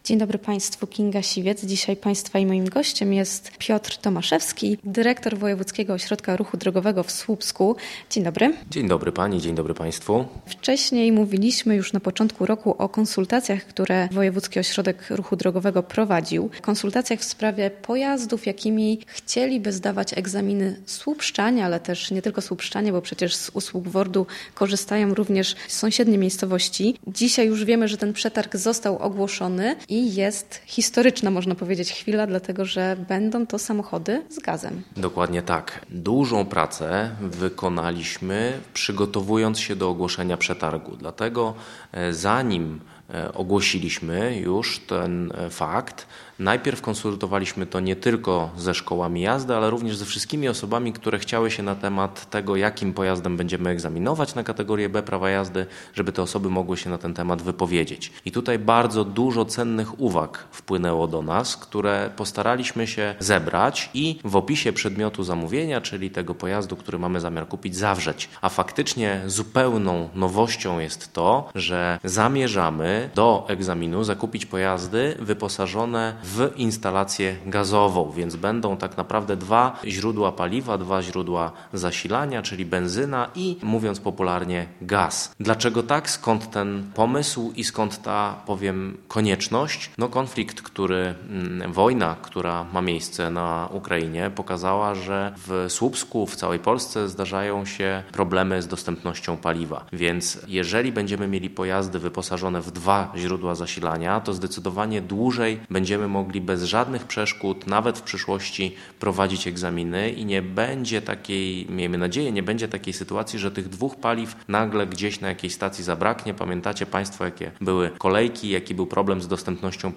Więcej na temat przetargu w rozmowie